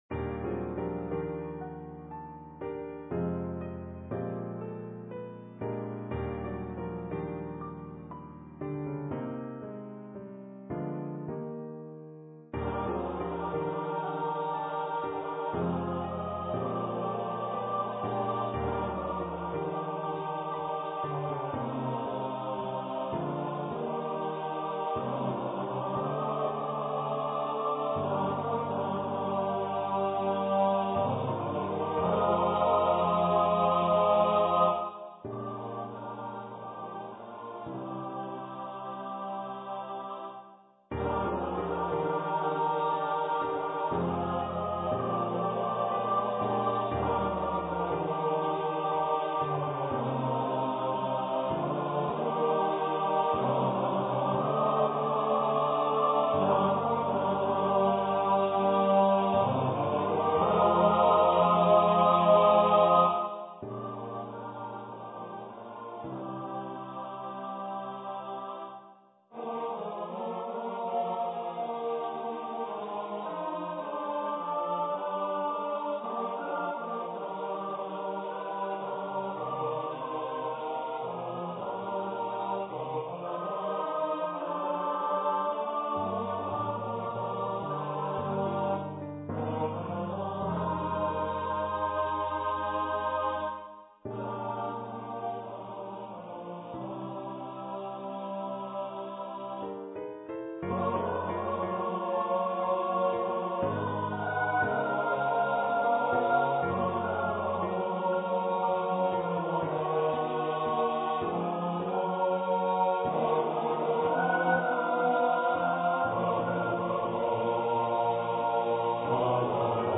for SATB choir